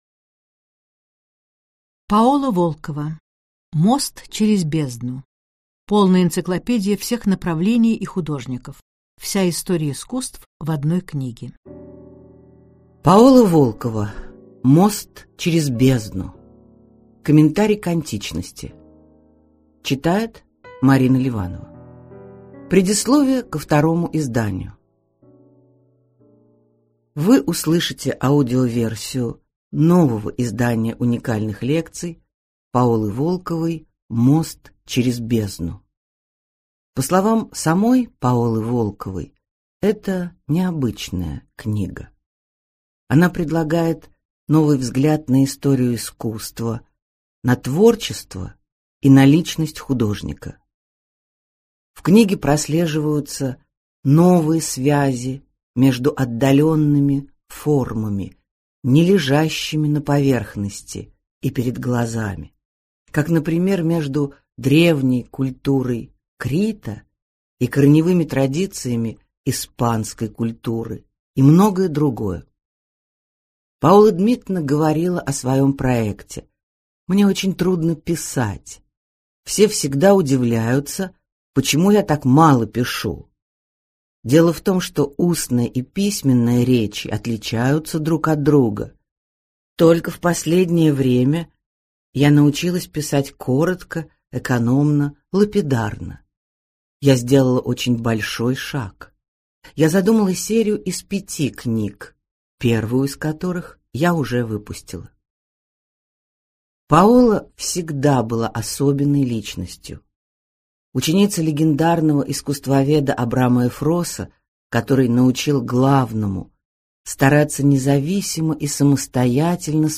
Аудиокнига Мост через бездну. Вся история искусства в одной книге | Библиотека аудиокниг